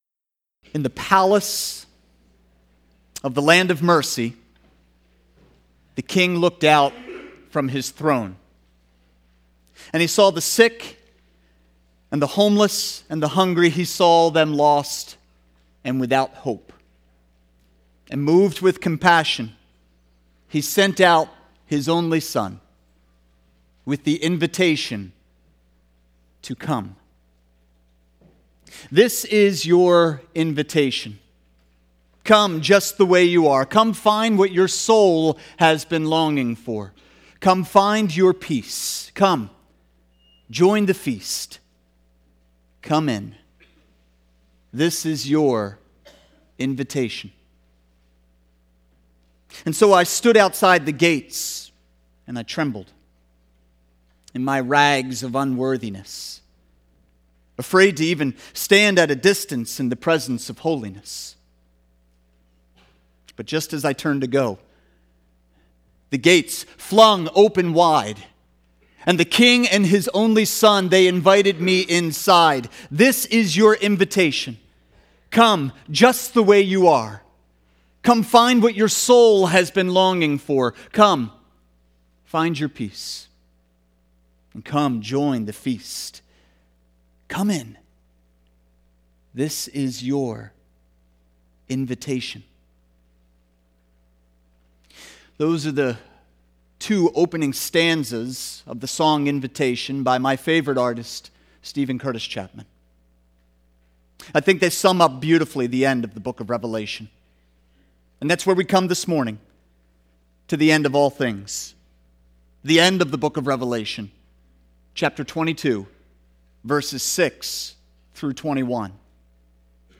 Sermons | Immanuel Church